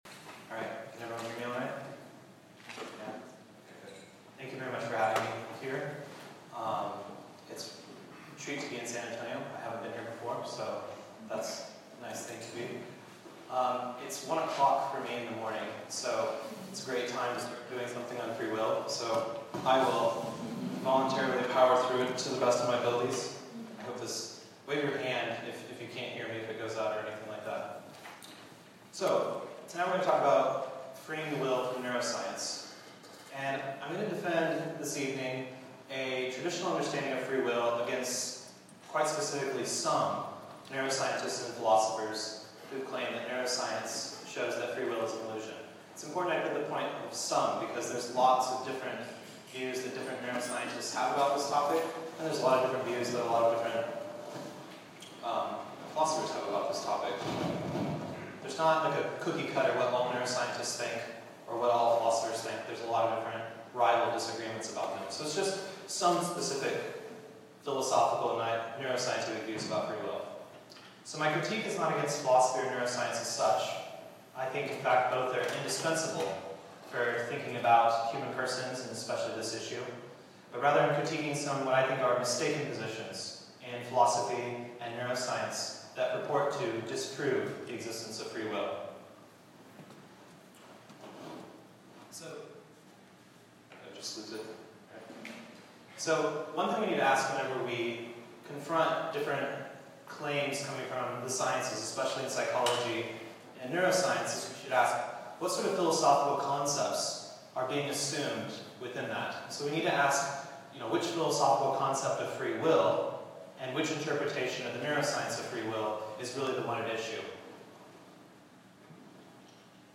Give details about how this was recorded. This lecture was given on November 6th, 2023, at Oxford University.